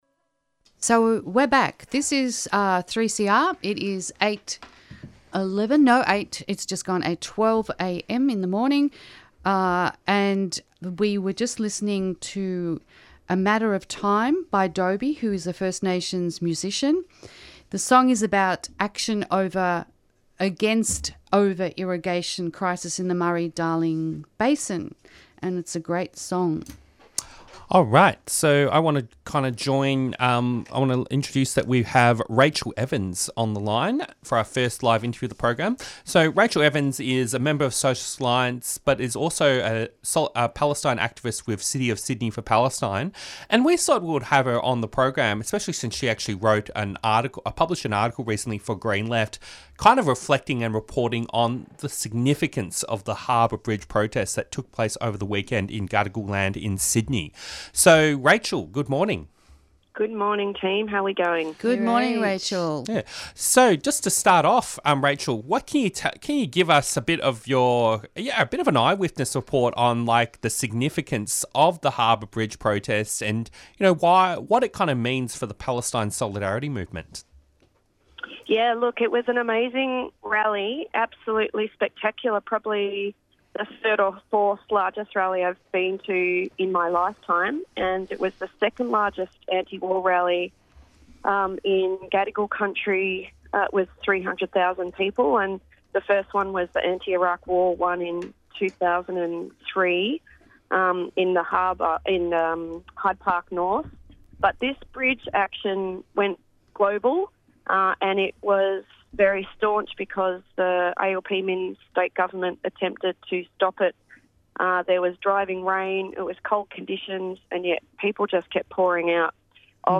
Interviews and Discussion